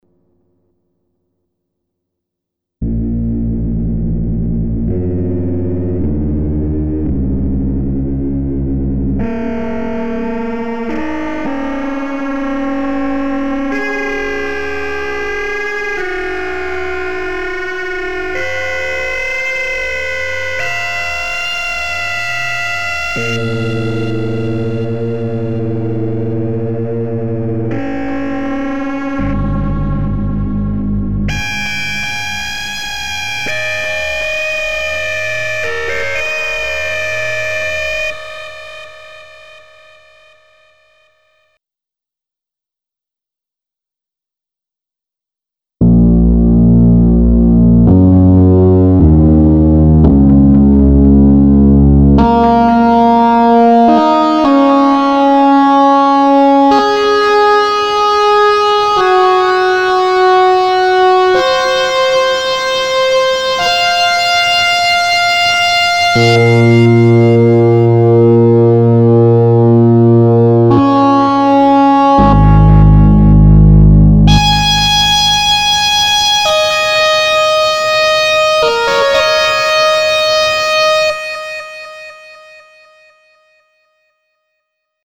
У x-station оч крутой дист/фидбек +реверы и прочие эффекты имхо конечно. Вот небольшое сравнение идентичного патча, вначале звучит железка (x(v)-station/powercore), потом v-station native.